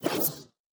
Cybernetic Technology Affirmation 12.wav